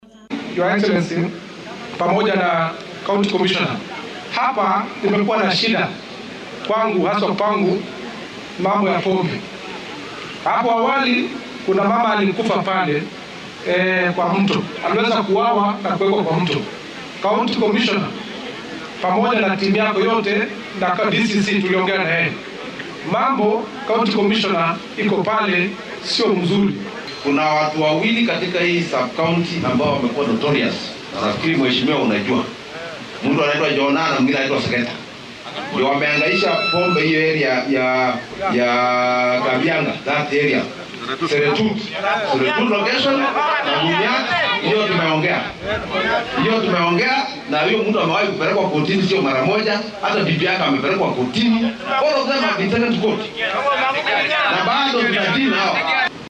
Wakiilka golaha deegaanka Seretet Cheptoloriet ee deegaanka Belgut Aaron Rotich ayaa ku dhaleeceeyay saraakiisha booliiska karti la’aantooda ku aaddan ciribtirka khamriga sharci darrada ah ee aagga Belgut. Waxaa uu hadalkan jeediyay xilli uu shalay ka qayb galay munnaasabada maalinta halyeeyada qaranka ee Mashujaa Day.